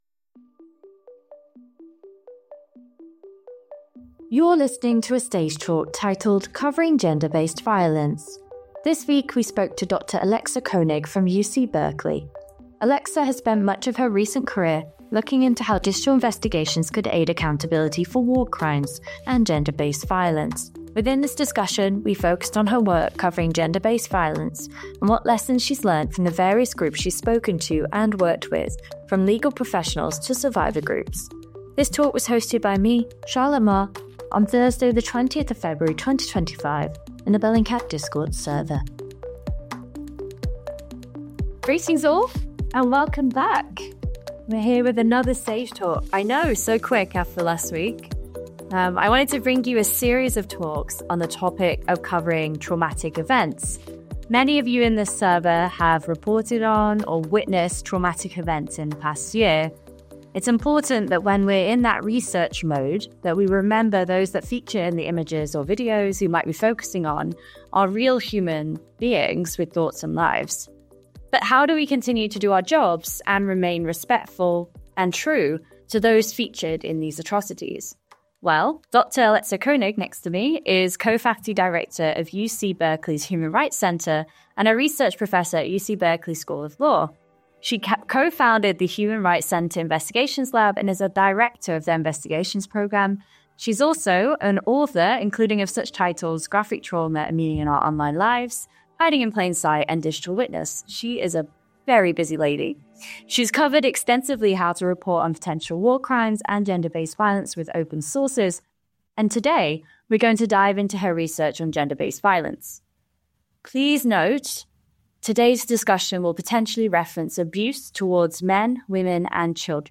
Join Bellingcat and a live audience in our discord server, where every month we host discussions with leading voices within open source research and investigations.